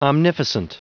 Prononciation du mot omnificent en anglais (fichier audio)
Prononciation du mot : omnificent